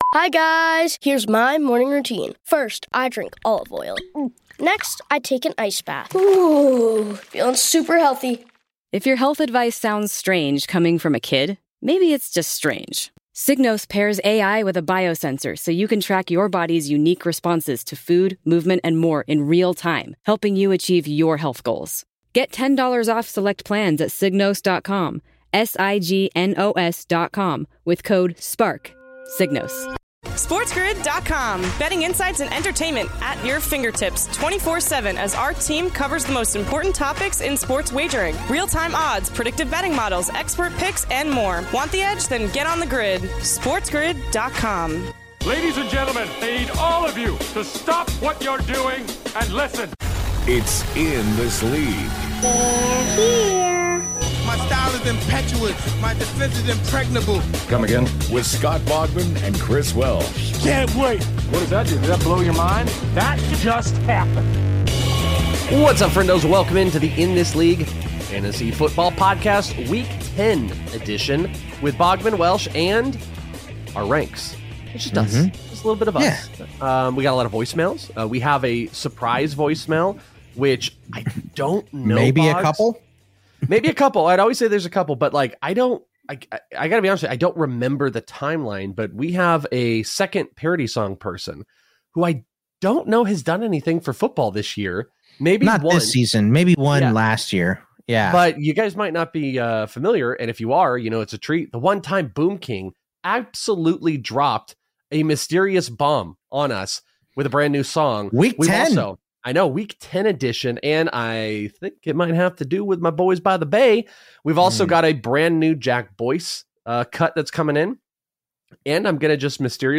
11/12 Hour 1: Fantasy Football Week 10, Listener Voicemails, Week 10 Ranks, & More